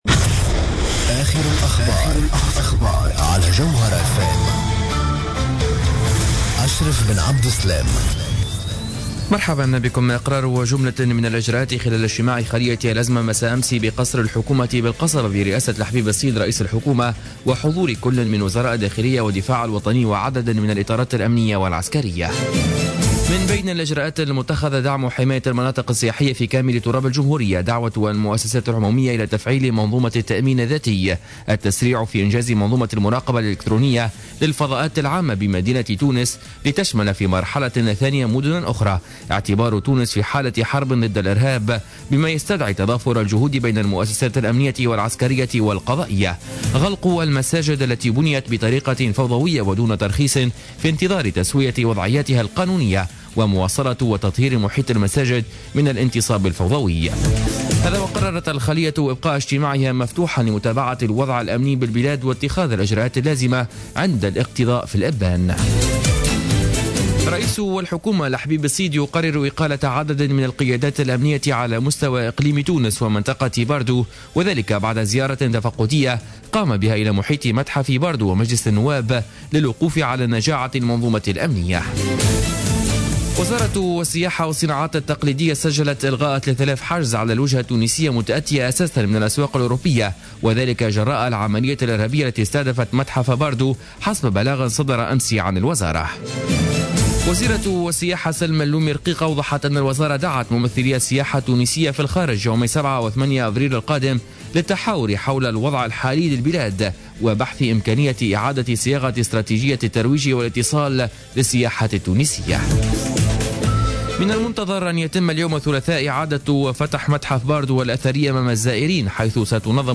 نشرة أخبار منتصف الليل ليوم الثلاثاء 24 مارس 2015